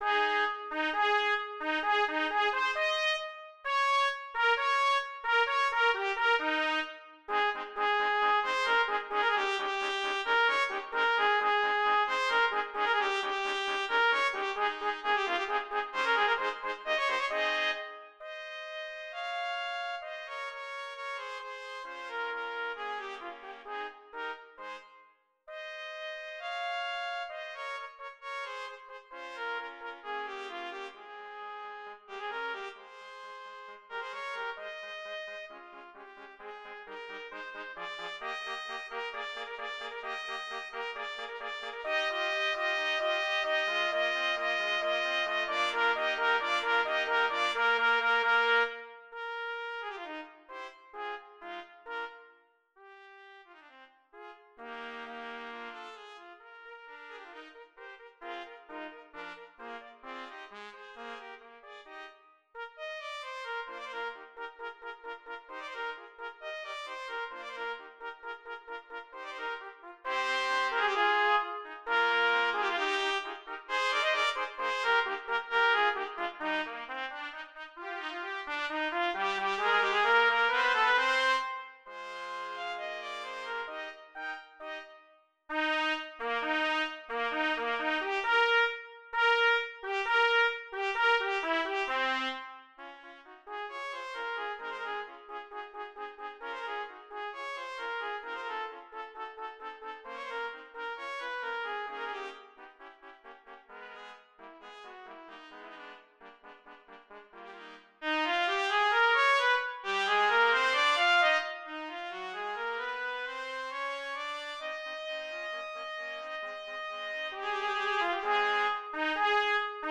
Voicing: Trumpet Quartet